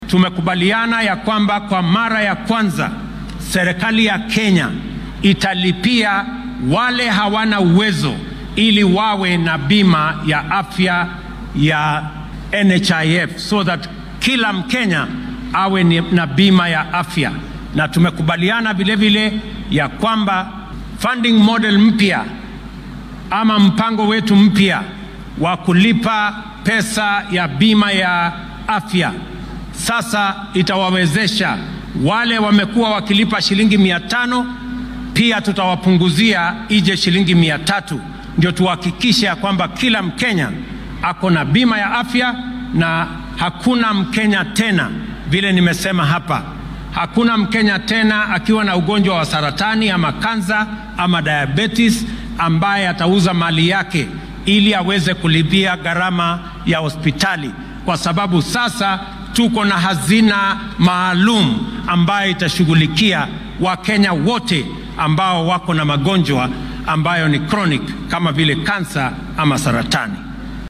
Xuska qaran ee maalinkan oo loo yaqaano Jamhuri Day ayaa lagu qabtay fagaaraha Uhuru Gardens ee magaalada Nairobi.
Madaxweynaha dalka William Ruto ayaa xilli uu maanta khudbad ka jeedinayay dabbaal degga qaran ee Jamhuri Day waxaa uu dhammaan kenyaanka uga mahadceliyay kaalintii ay ka qaateen in laga soo gudbo xilli adag oo uu dalka soo maray.